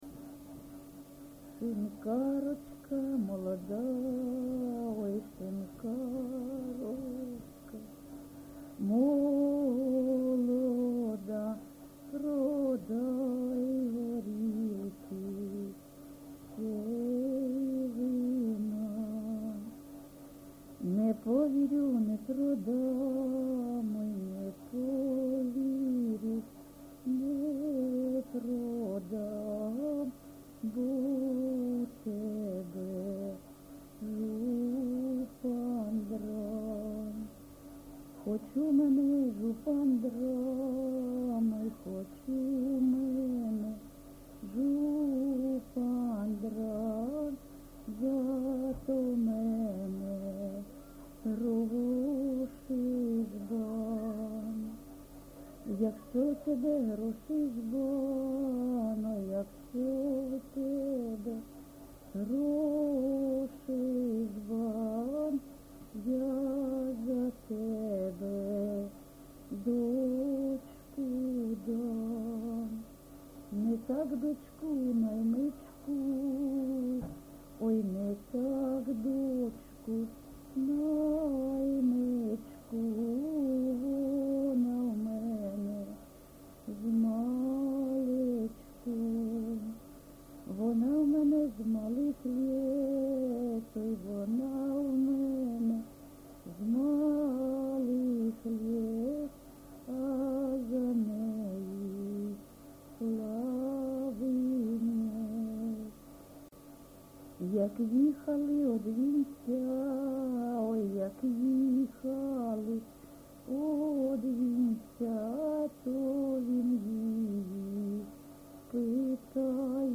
ЖанрПісні з особистого та родинного життя
Місце записум. Бахмут, Бахмутський район, Донецька обл., Україна, Слобожанщина